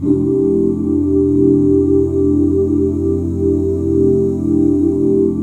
FMAJ7 OOO.wav